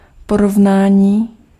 Ääntäminen
US GenAm: IPA : /kəmˈpɛɹɪsən/ IPA : /kəmˈpæɹɪsən/ RP : IPA : /kəmˈpæɹɪsən/